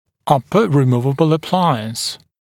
[‘ʌpə rɪ’muːvəbl ə’plaɪəns][‘апэ ри’му:вэбл э’плайэнс]съёмный верхнечелюстной аппарат